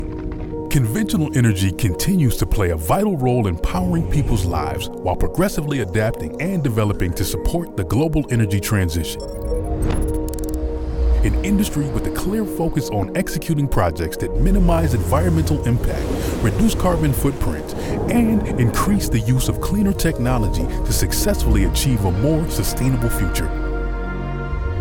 Best Male Voice Over Actors In April 2026
Adult (30-50)